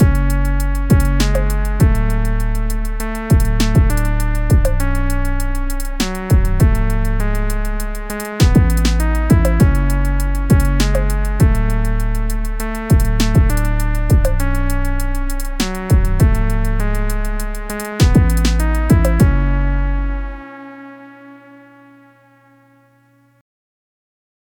Just used a lil trappy beat I had on my M:S, and made sure to carefully align everything so it was in phase.
I didn’t get the hardware plugin part set up so everything is recorded through my audio interface into Reaper from the pedal’s stereo output.
Pedal Settings were max Volume, min Sustain (adjusting M:S volume to taste for each distortion type), tone bypassed, wicker ON as the mix is very muddy and dull without it.